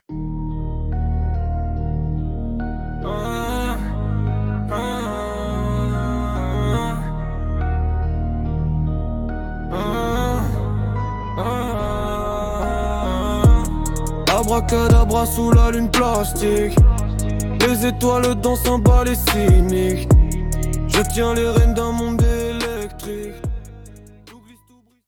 Style : Rap